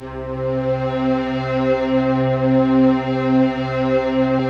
Index of /90_sSampleCDs/Optical Media International - Sonic Images Library/SI1_OrchestChoir/SI1_Dark&moody